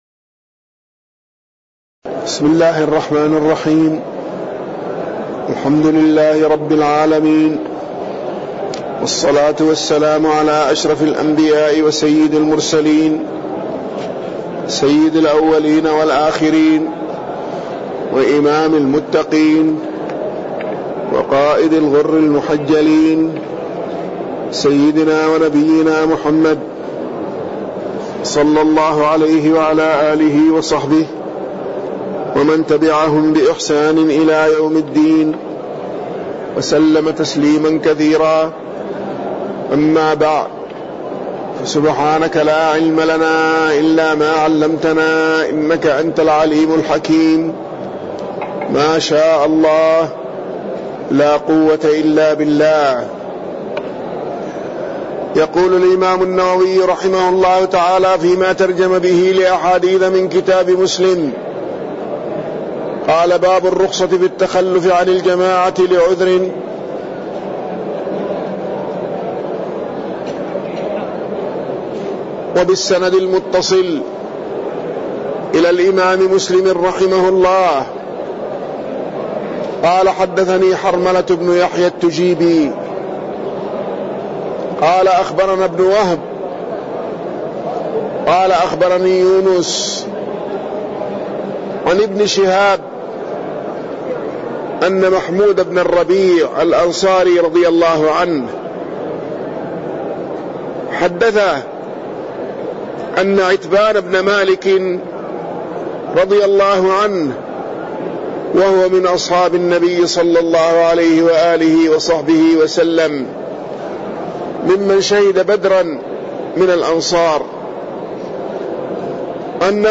تاريخ النشر ١٣ ربيع الأول ١٤٣٠ هـ المكان: المسجد النبوي الشيخ